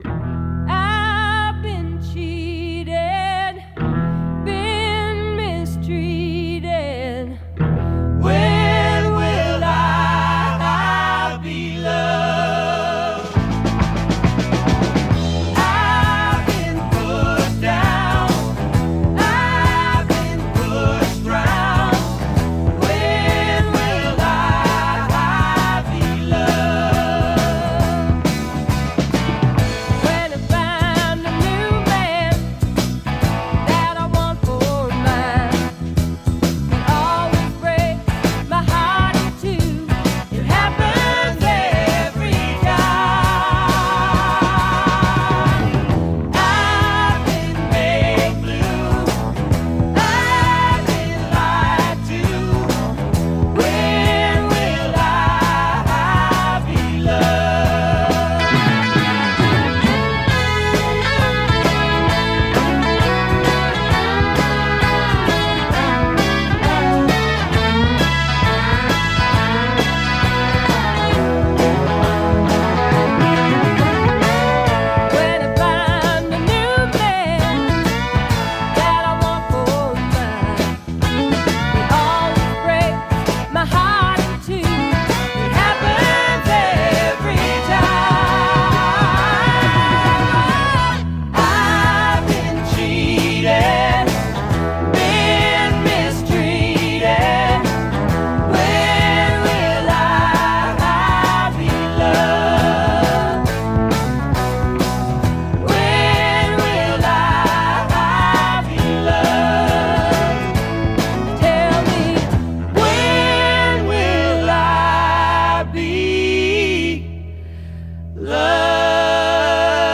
BPM88-138
Audio QualityMusic Cut